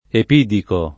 Epidico [ ep & diko ]